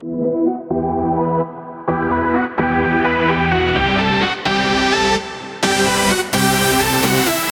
Saw, supersaw, chord progression, top melody, E minor, rising low-pass, , 4 bars, 128BPM, 1.mp3